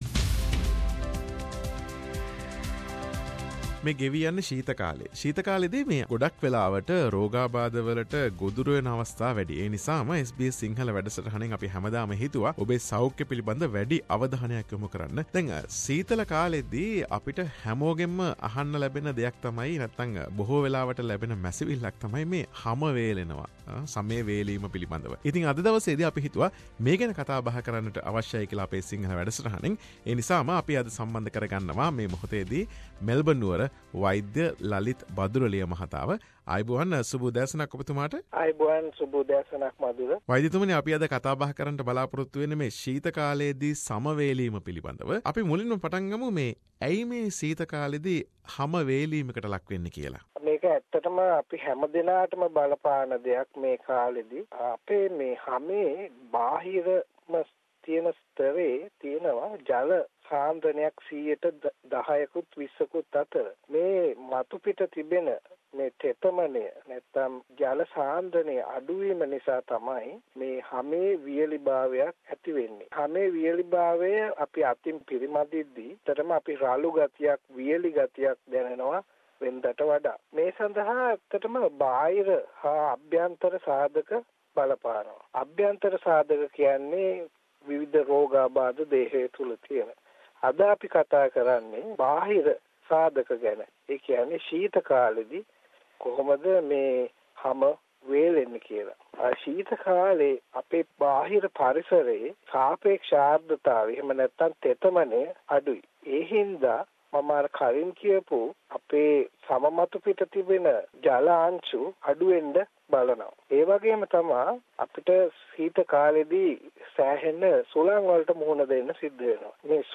වෛද්‍ය සාකච්ඡාවට